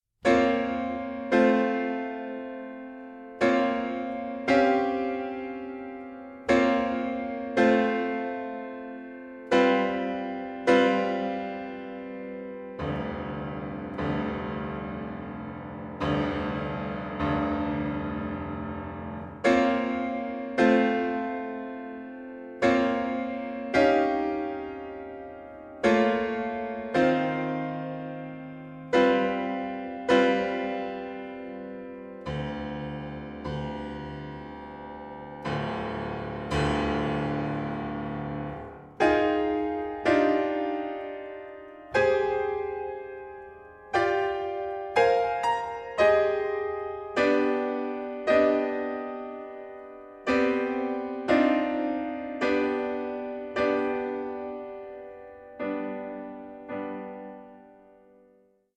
Pianist
PIANO MUSIC